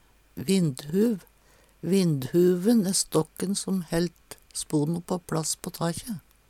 vindhuv - Numedalsmål (en-US)
Tilleggsopplysningar "vinnhuv" blir og bruka Høyr på uttala Ordklasse: Substantiv hankjønn Kategori: Bygning og innreiing Attende til søk